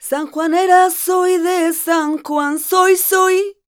46a05voc-f#m.wav